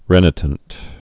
(rĕnĭ-tənt, rĭ-nītnt)